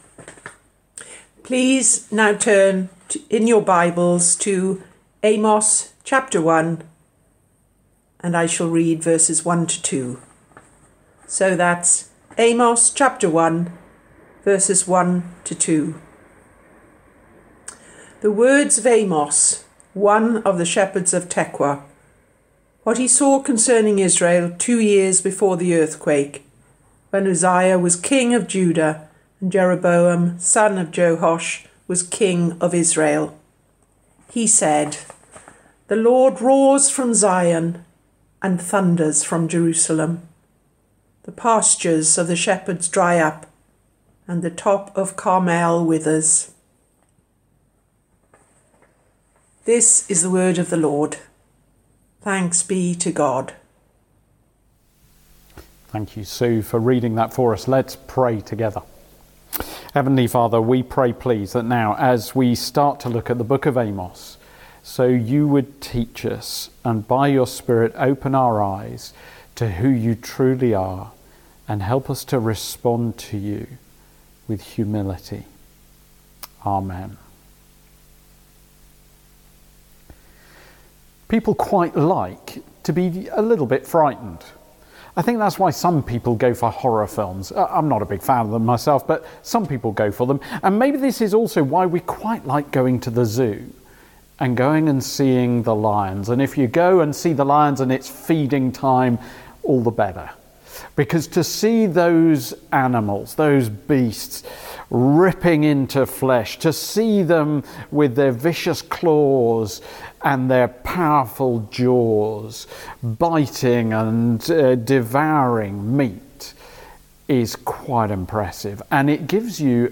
Sunday Service 6 September 2020 – Amos 1:1-2